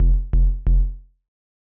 KIN Bass Riff F.wav